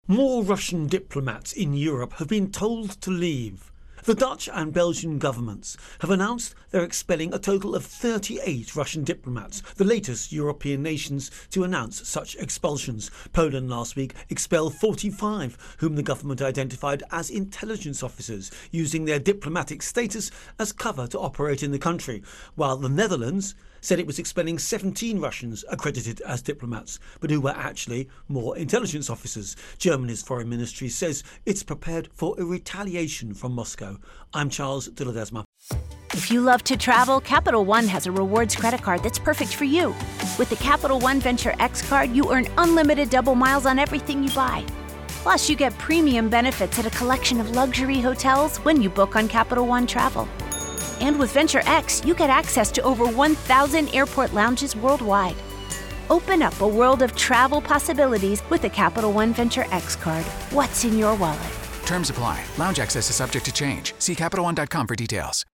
Europe-Russia-Expulsions Intro and Voicer